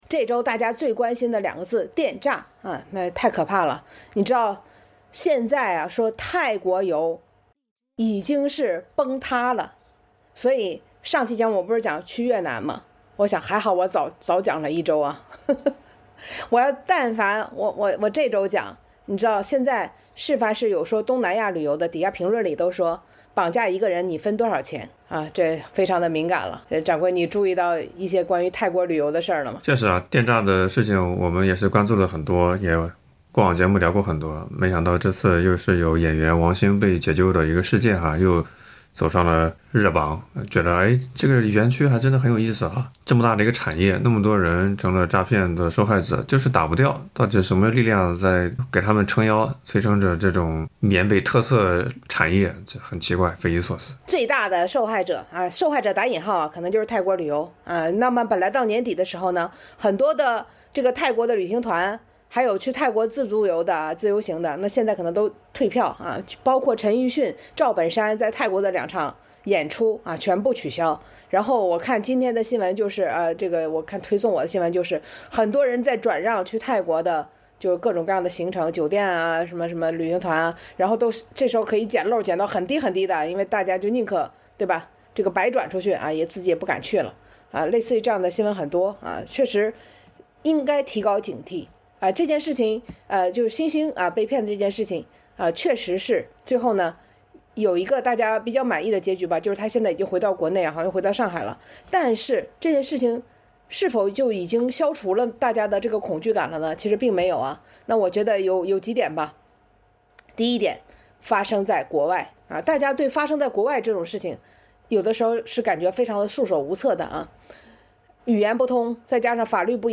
这期节目里，主播回顾了菲律宾绑架华人大案，和震惊的结局。